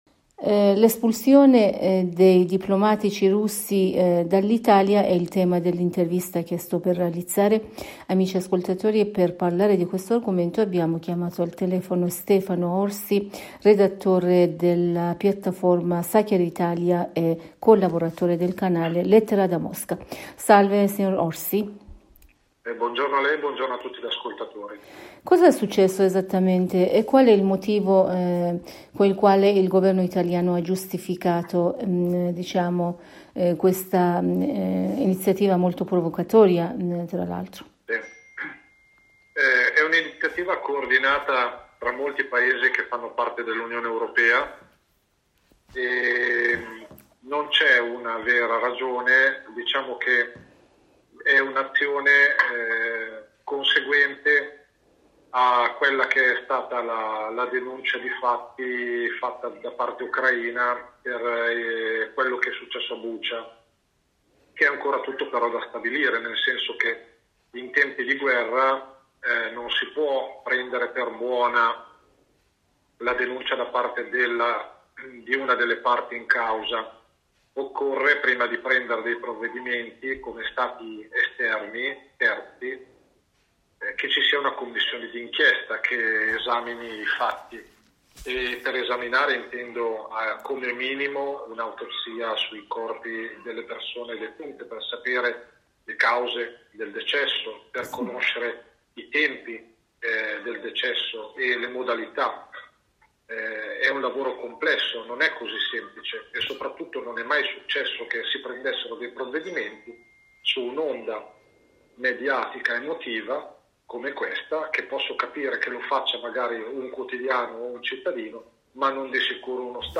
in un collegamento telefonico con la Radio Italia della Voce della Repubblica islamica dell'Iran (IRIB)
intervista